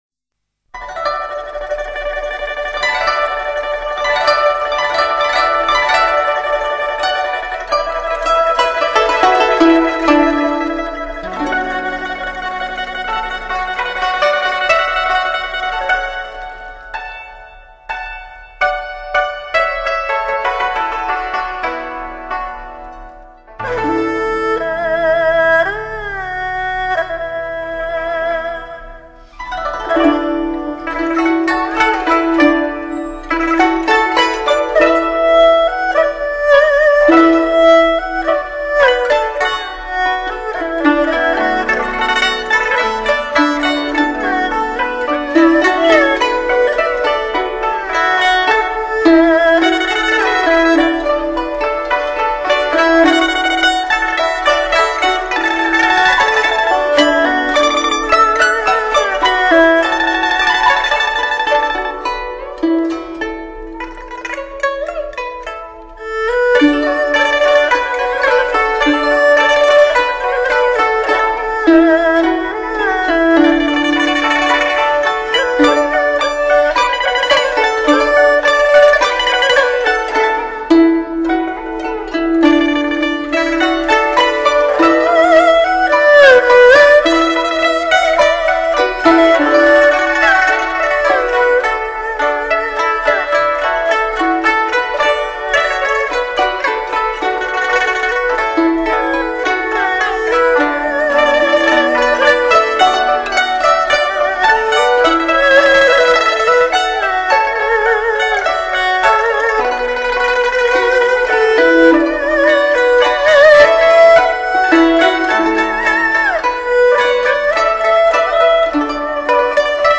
本碟收录了我国优秀的民族乐曲，淡而清雅，